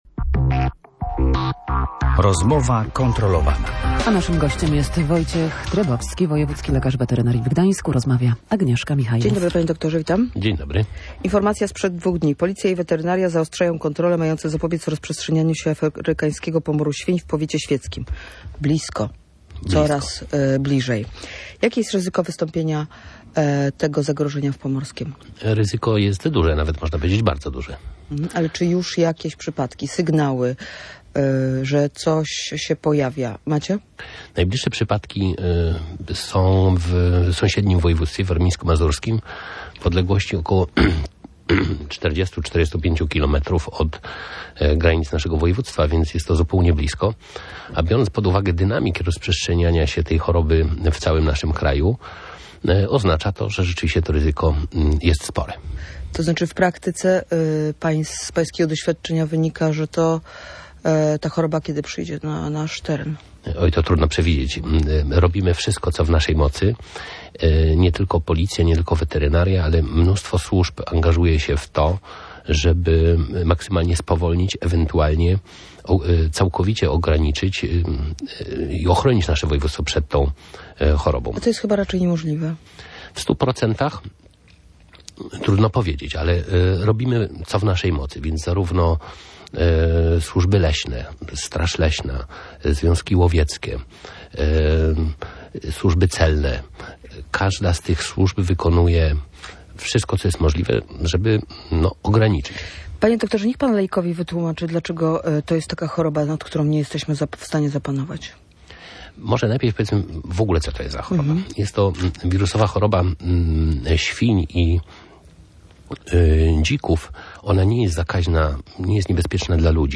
Duże zagrożenie wystąpienia afrykańskiego pomoru świń w województwie pomorskim. Policja i weterynaria zaostrzają kontrolę, mającą zapobiegać rozprzestrzenianiu się zagrożenia. – Biorąc pod uwagę to, jak szybko rozprzestrzenia się choroba, ryzyko pojawienia się jej w naszym województwie jest bardzo duże – mówił gość Radia Gdańsk.